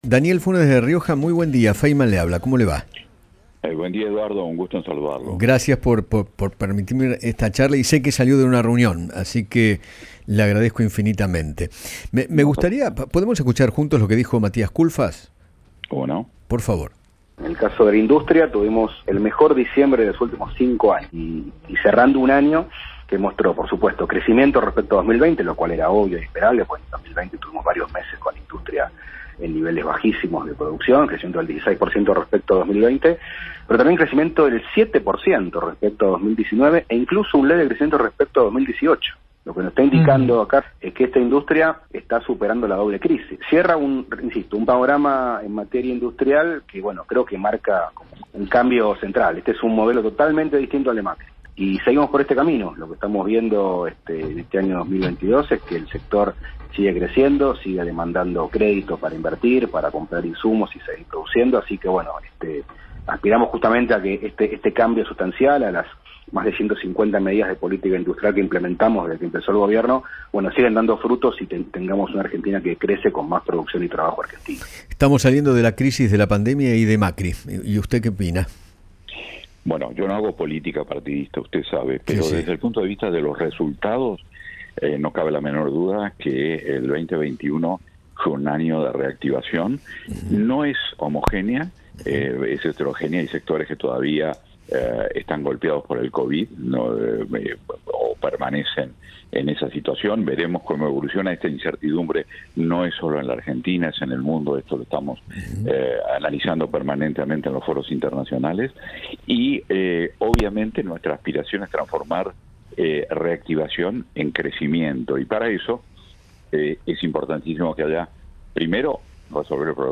El presidente de UIA, Daniel Funes de Rioja, conversó con Eduardo Feinmann sobre los dichos del ministro de Desarrollo Productivo de la Nación, Matías Kulfas, quien aseguró que “las políticas nos siguen dando frutos”, y analizó el presente financiero del país.